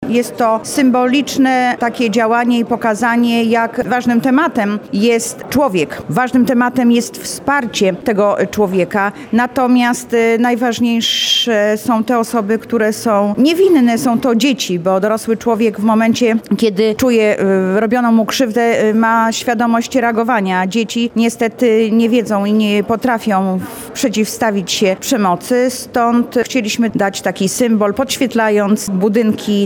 – Każdy człowiek powinien być bezpieczny, a dzieci to jedna z tych grup społecznych, o które powinniśmy się szczególnie troszczyć – mówi zastępca prezydenta Lublina do spraw społecznych Anna Augustyniak.